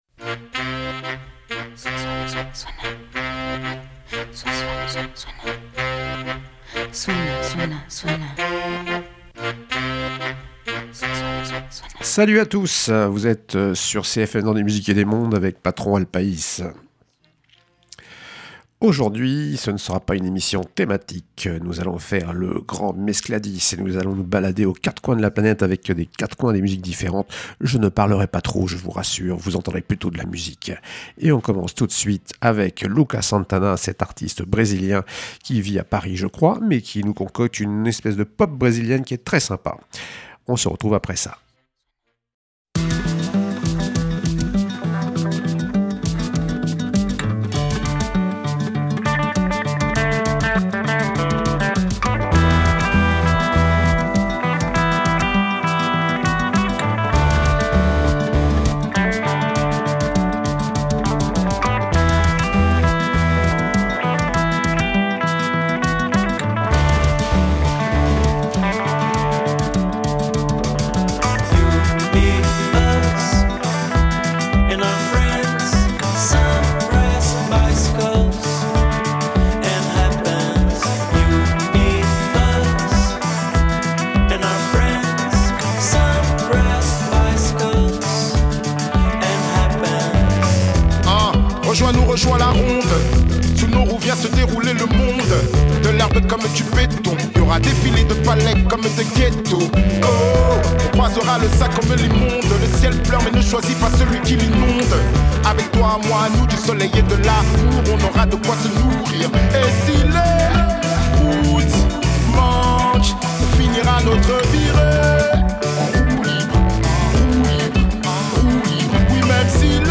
Une mescladis de musique presque sans parole, au programme : Brésil, Colombie, Mexique, Cuba.